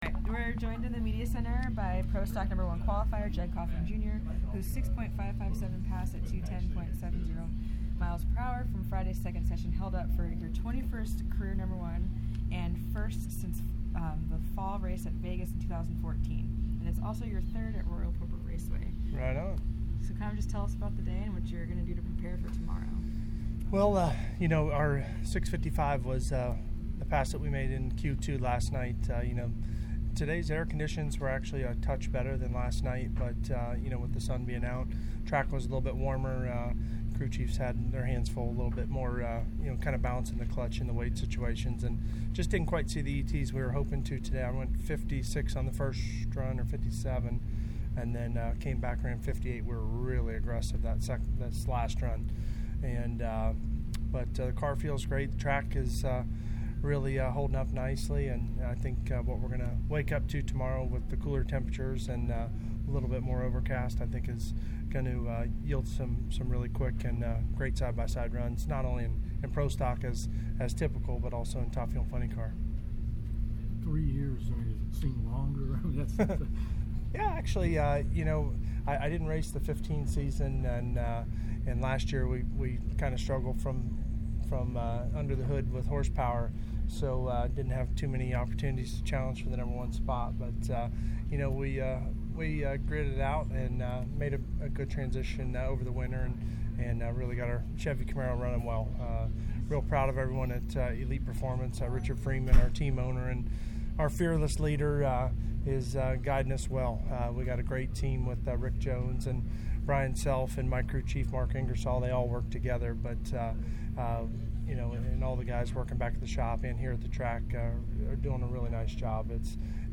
Interviews from the media center: